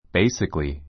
basically A2 béisikəli ベ イスィカり 副詞 基本的に, 根本的に I believe humans are basically good.